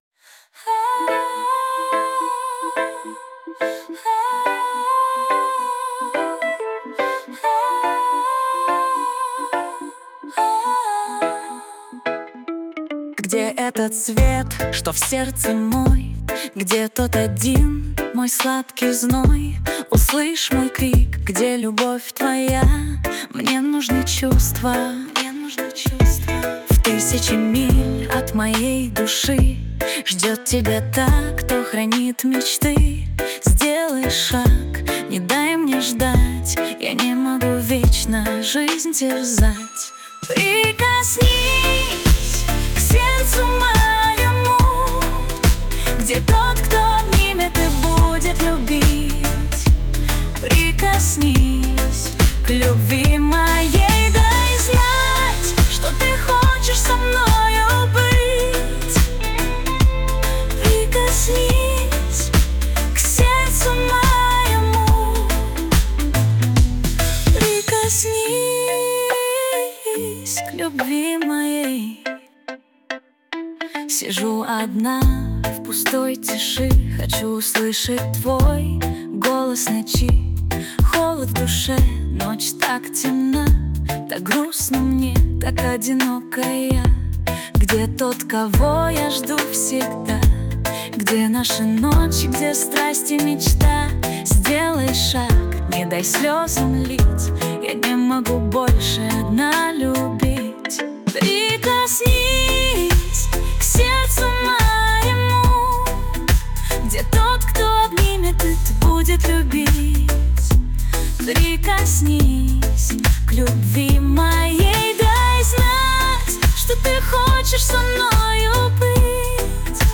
RUS, Dance, Pop, Disco | 16.03.2025 10:11